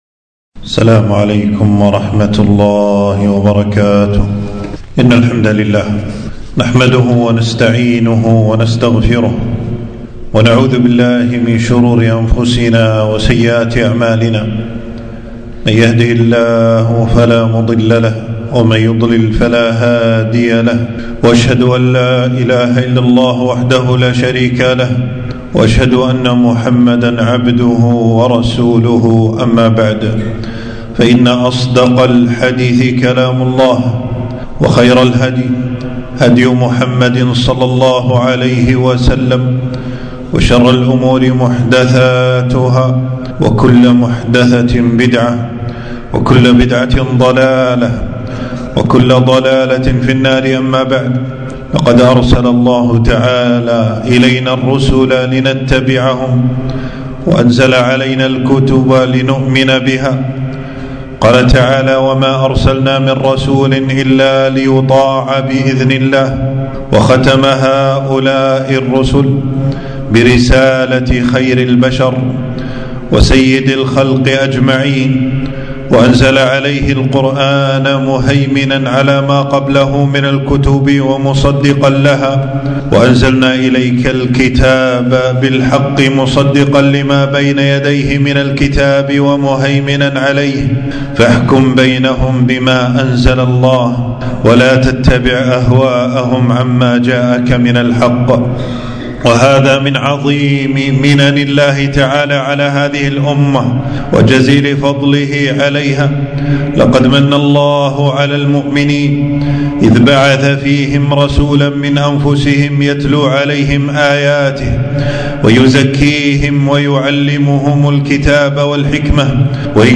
خطبة - السنة سفينة نوح من ركبها نجا